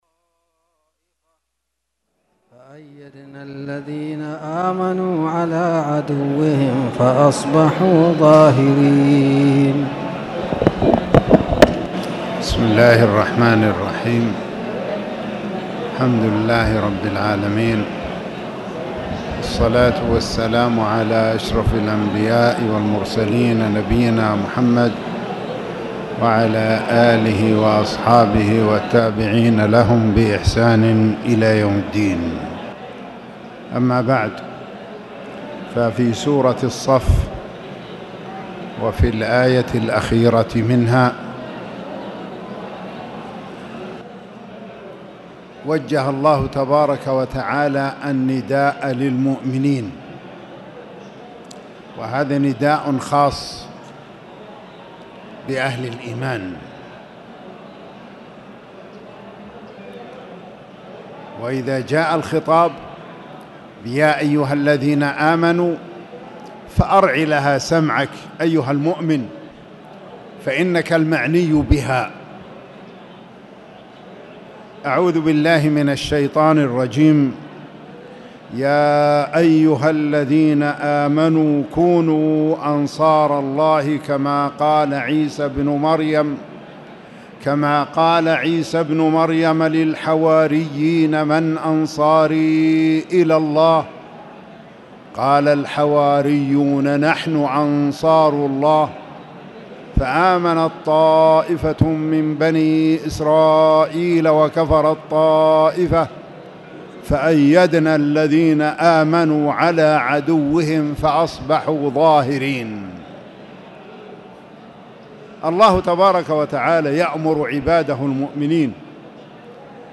تاريخ النشر ١ جمادى الأولى ١٤٣٨ هـ المكان: المسجد الحرام الشيخ